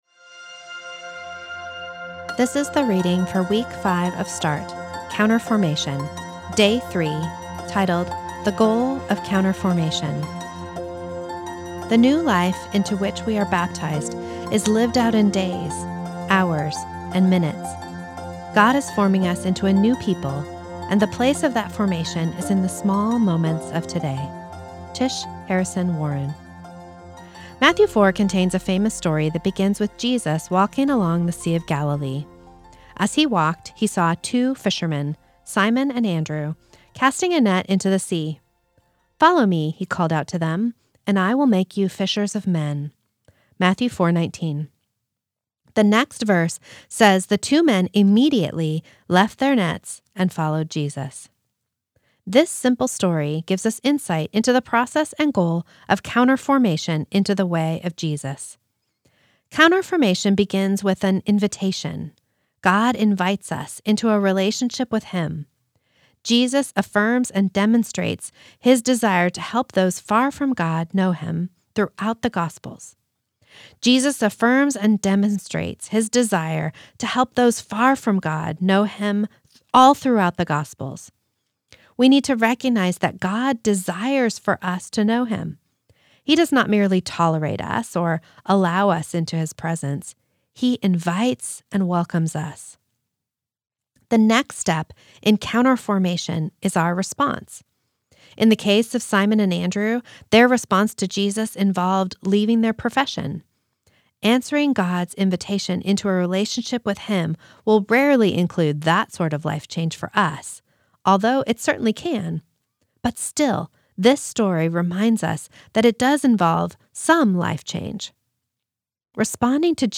This is the audio recording of the third reading of week four of Start, entitled Involving Others.